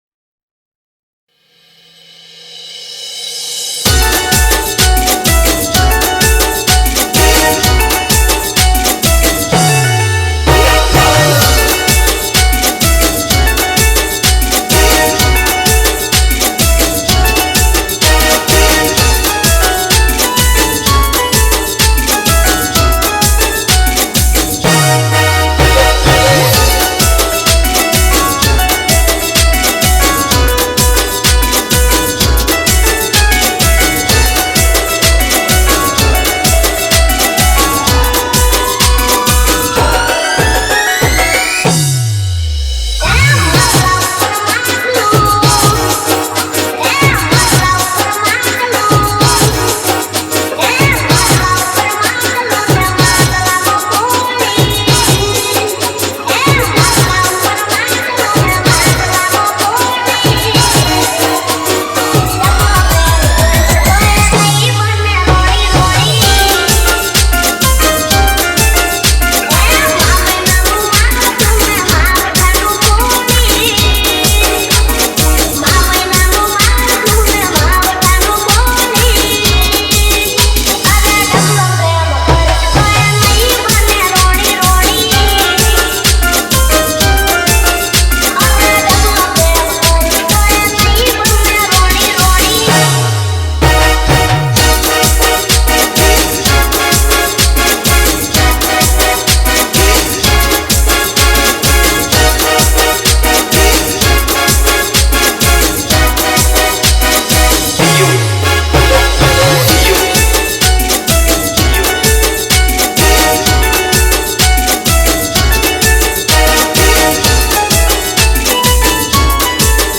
Dj Remix Gujarati